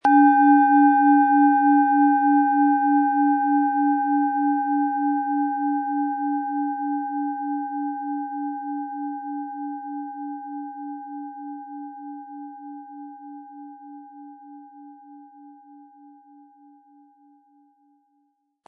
Planetenschale® Kraftvoll und Aktiv fühlen & Energetisch sein mit Mars, Ø 11,7 cm, 180-260 Gramm inkl. Klöppel
Planetenton 1
Um den Original-Klang genau dieser Schale zu hören, lassen Sie bitte den hinterlegten Sound abspielen.